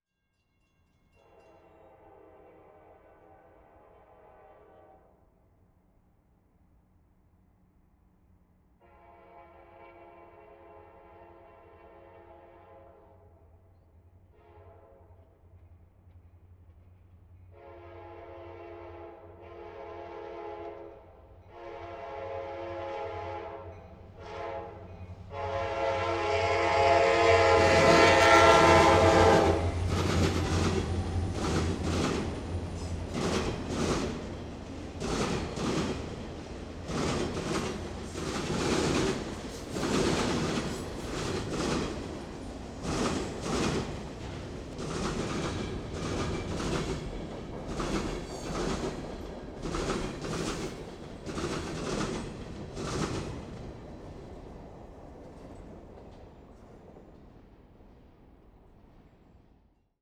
Tetrahedral Ambisonic Microphone
Recorded January 21, 2010, at the crossing of the Union Pacific and Austin and Western railroads, McNeil, Texas